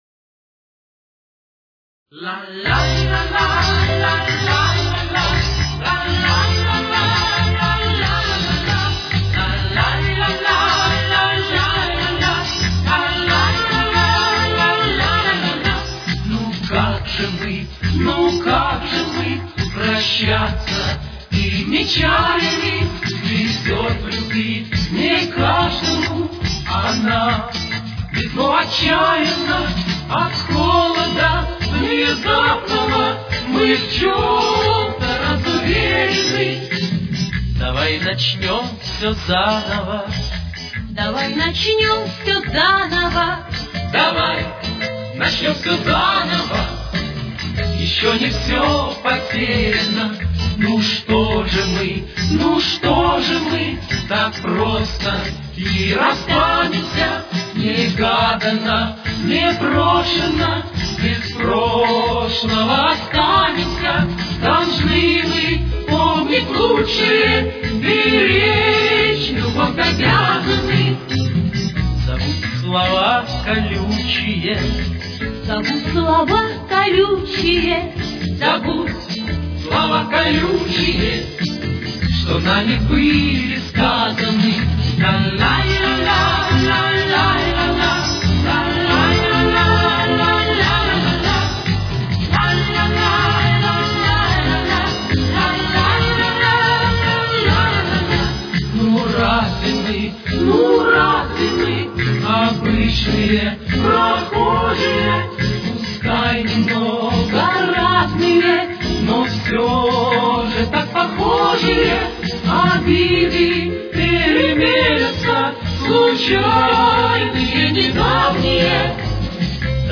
Тональность: Ля минор. Темп: 143.